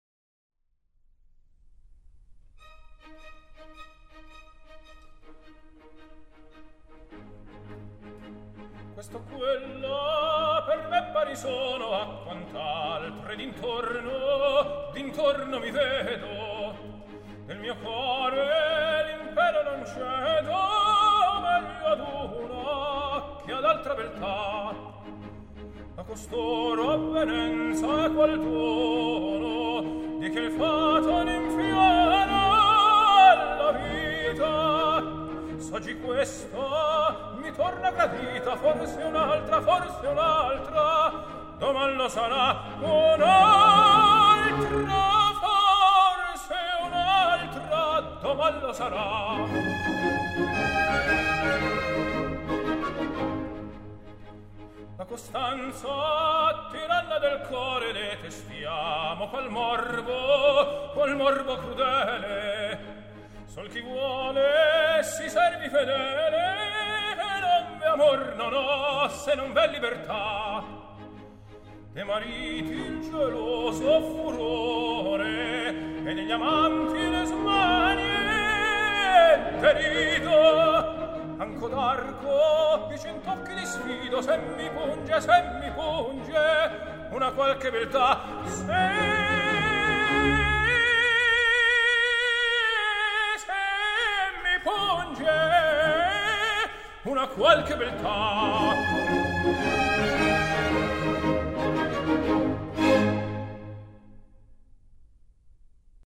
类别：古典音乐